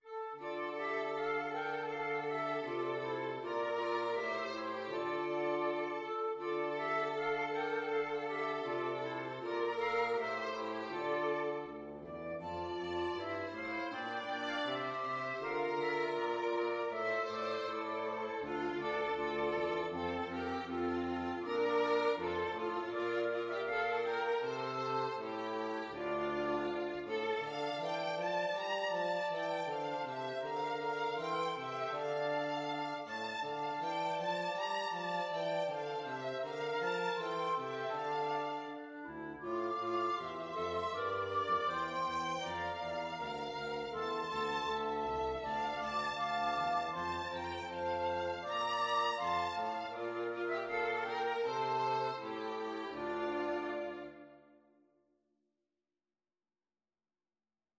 Free Sheet music for Flexible Mixed Ensemble - 4 Players
Flute
Violin
Clarinet
Traditional Music of unknown author.
D minor (Sounding Pitch) (View more D minor Music for Flexible Mixed Ensemble - 4 Players )
2/4 (View more 2/4 Music)